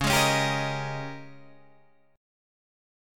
A#m13/C# chord